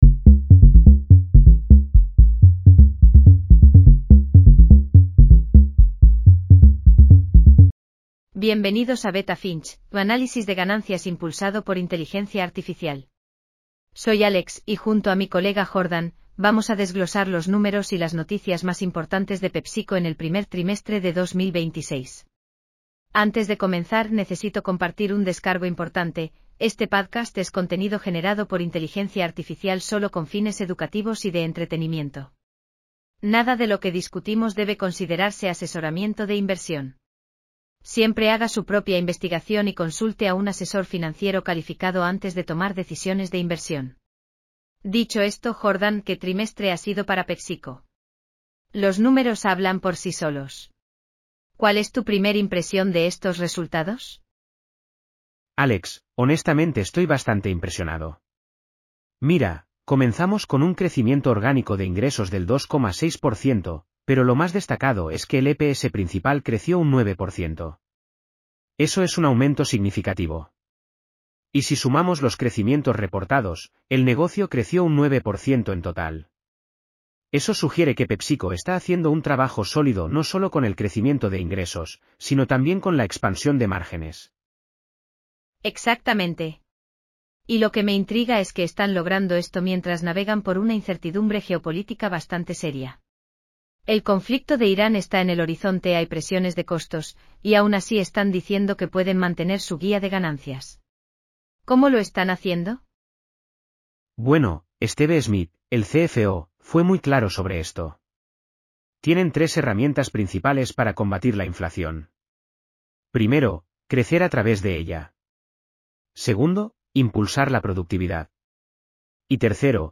PepsiCo Q1 2026 earnings call breakdown.